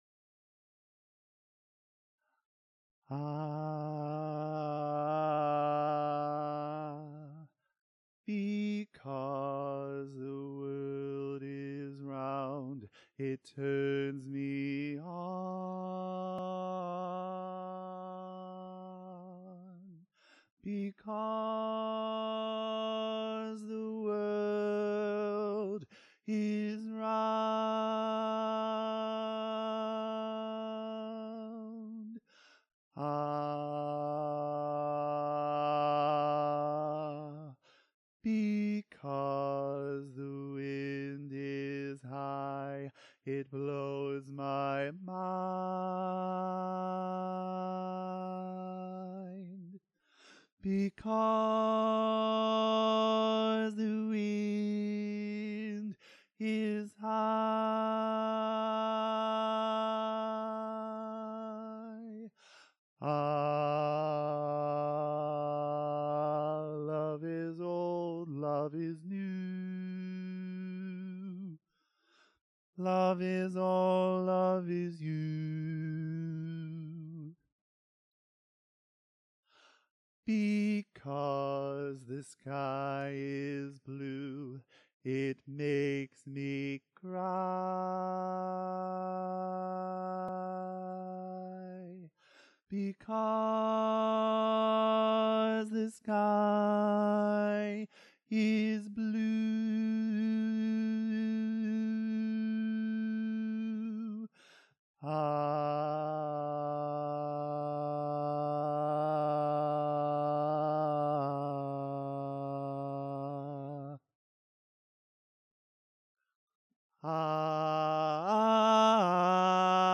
Bass Instrumental